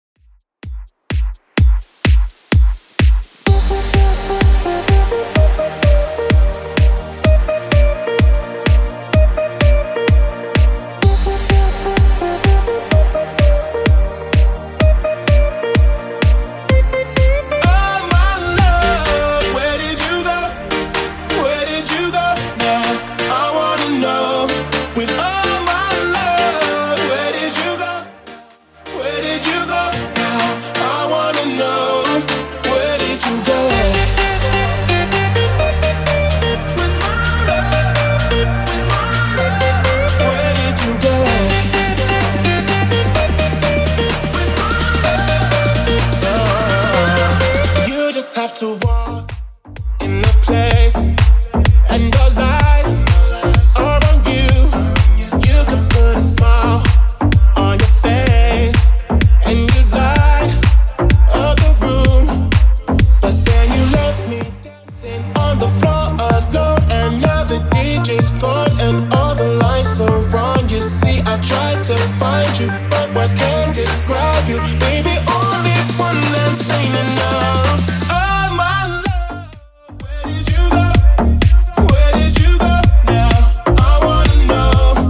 Genre: Version: BPM: 127 Time: 3:24